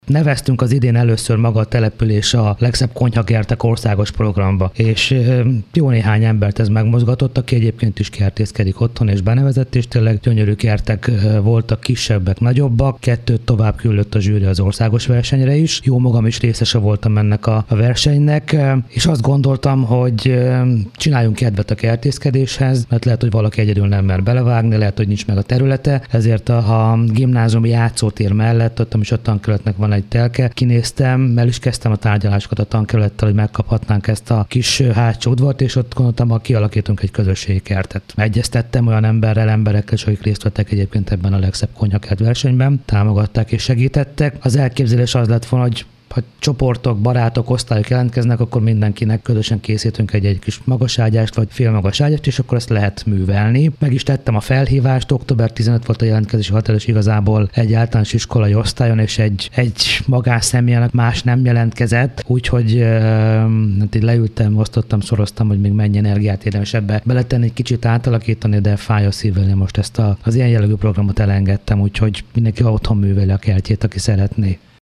Folyamatosan igyekeztek energia hatékony épületeket létrehozni Újhartyánon az utóbbi években, így most a legtöbb helyen nincs szükség megszorításokra. Schulcz József polgármester arról beszélt, a nagy energiaveszteséggel működő épületeket inkább kiürítik.